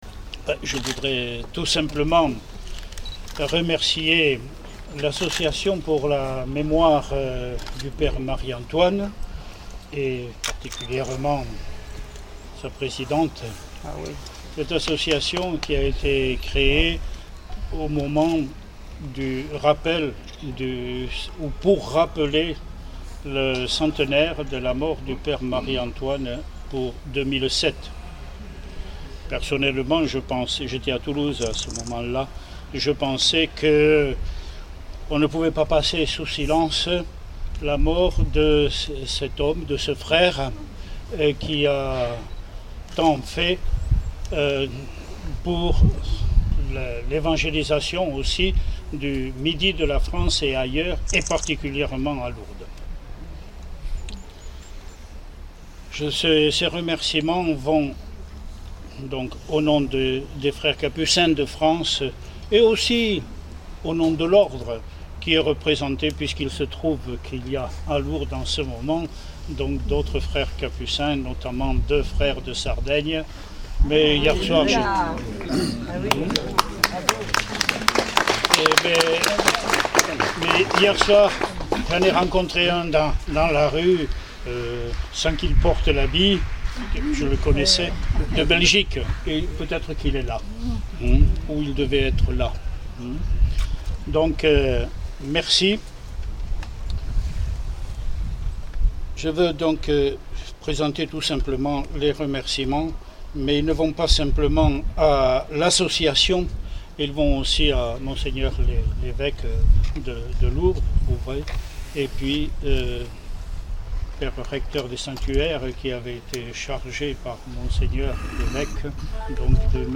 4 - Mgr François Fonlupt, évêque de Rodez
5 - Mgr Robert Le Gall, archevêque de Toulouse